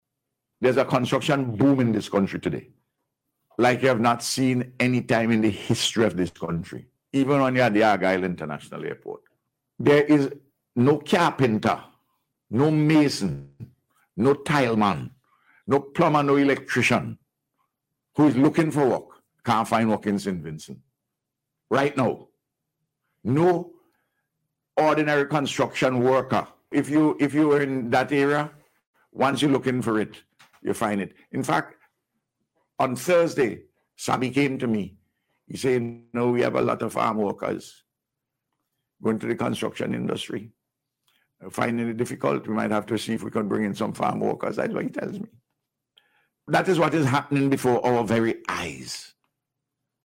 Speaking on radio on Sunday, the Prime Minister noted that every sector of society has benefitted from significant improvements